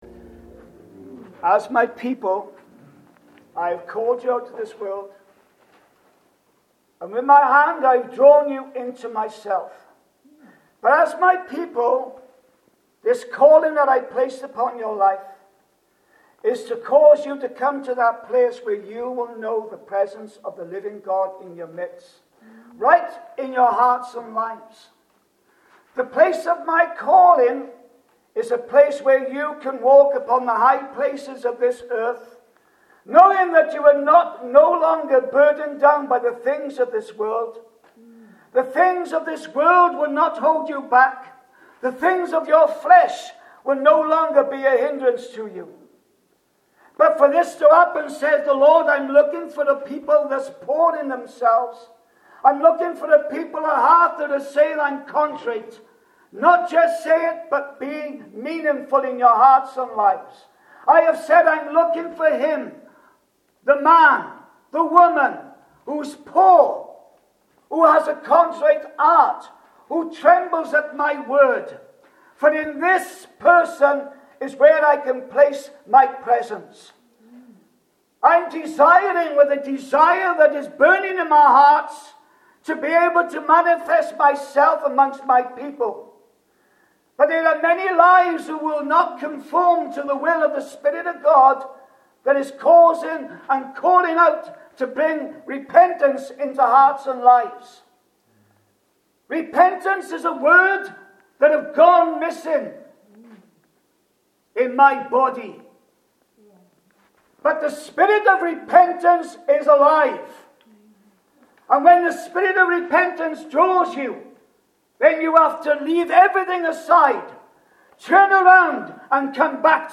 Note: This article is based on a real church recording .